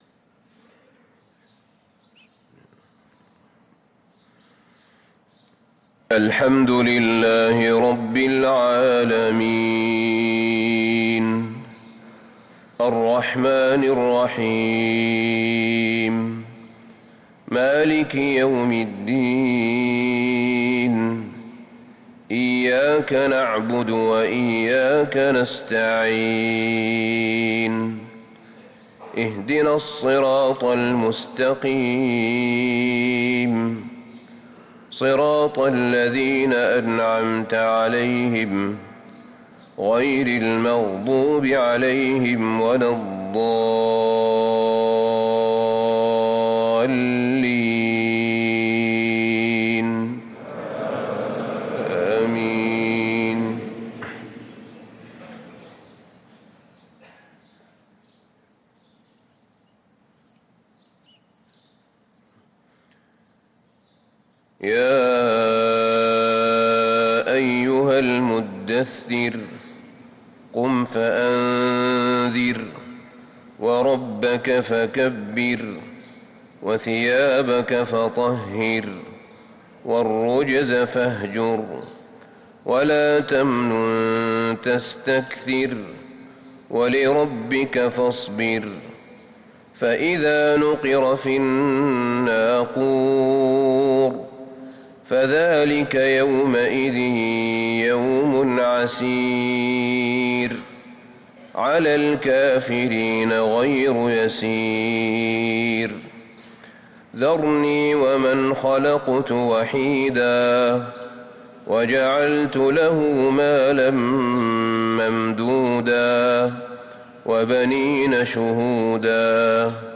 صلاة الفجر للقارئ أحمد بن طالب حميد 21 شعبان 1444 هـ